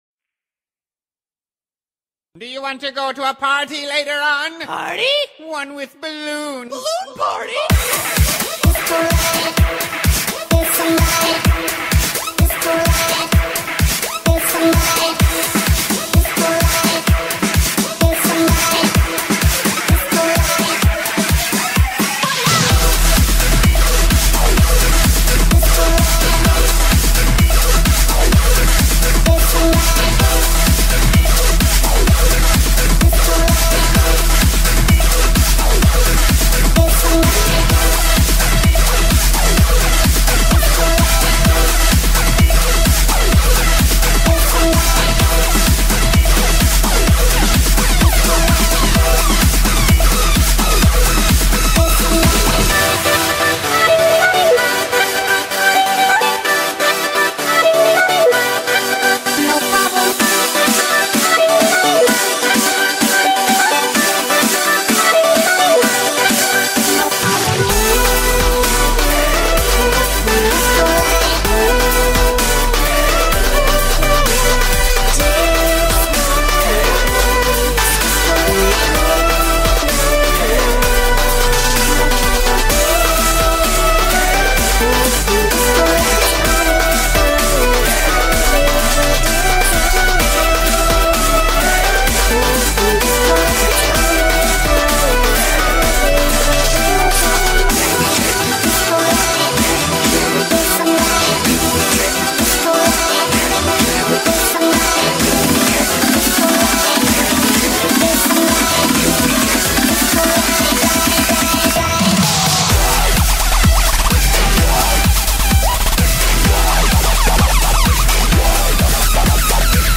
genre:house